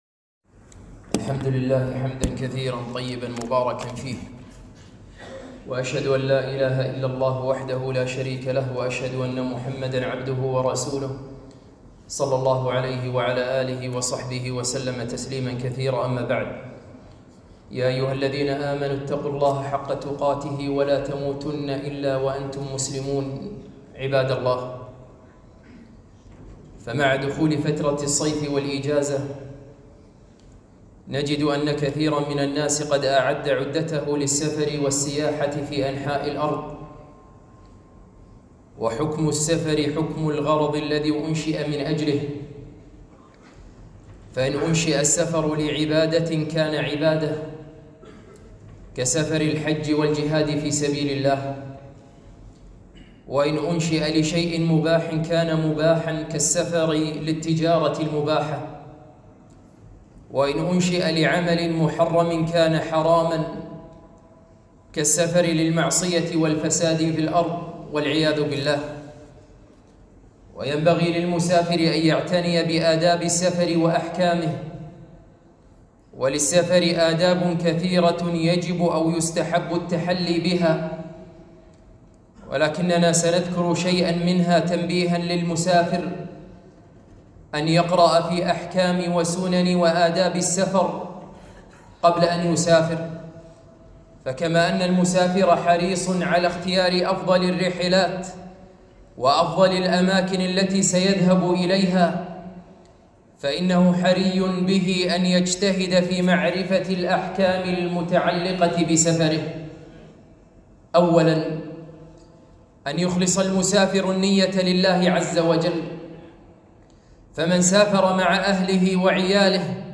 خطبة - رسـالـةٌ إلى مـسـافـر